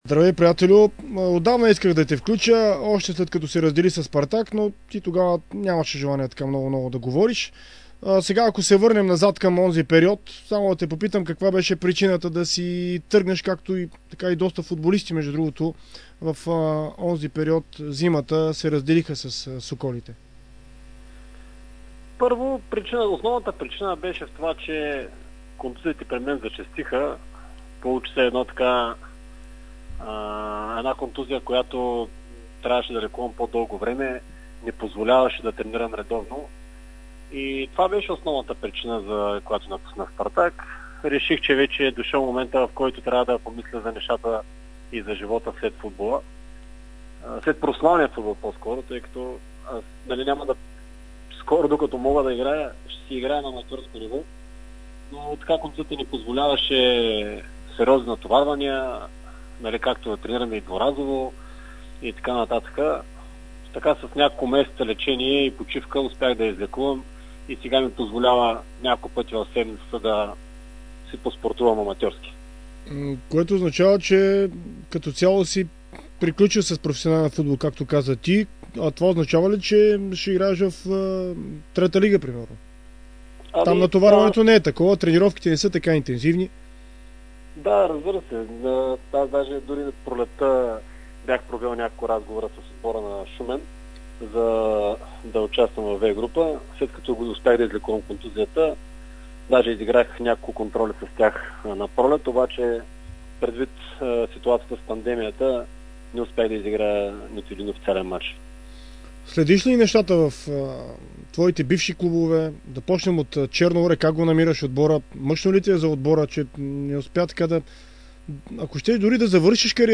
говори в интервю за Дарик радио и dport за причините да спре с професионалния футбол.